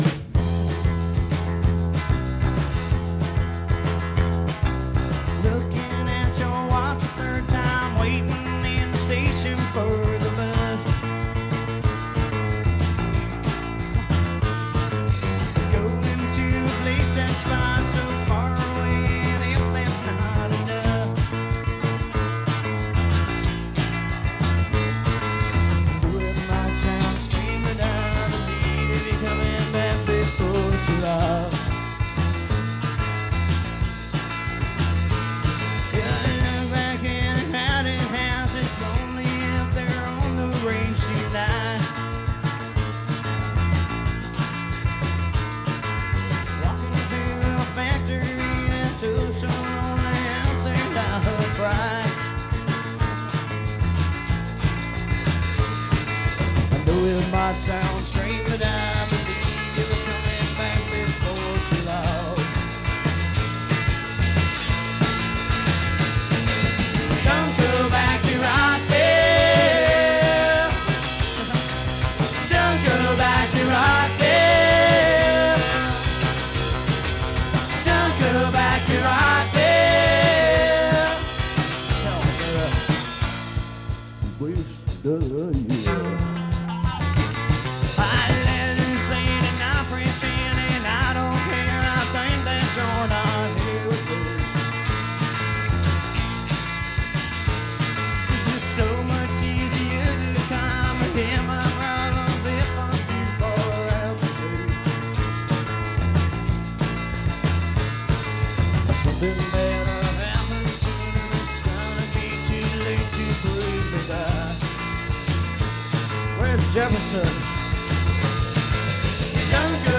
Merlins, Madision, WI